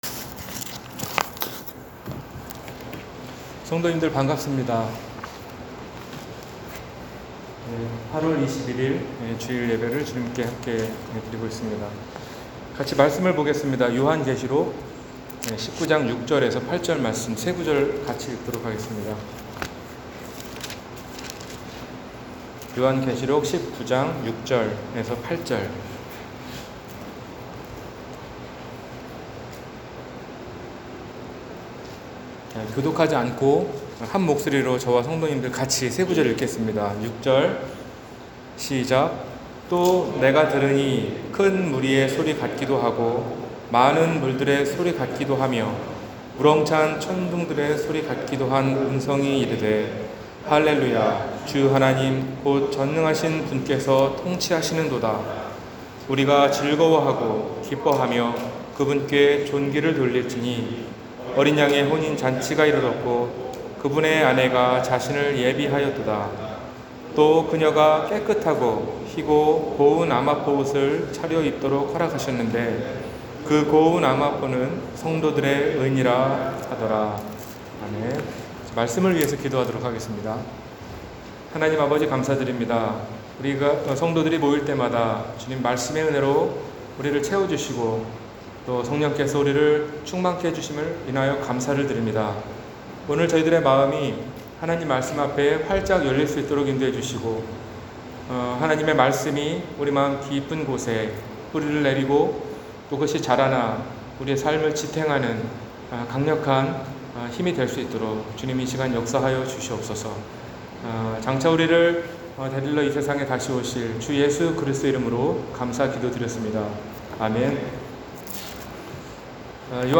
어린양의 혼인잔치 – 주일설교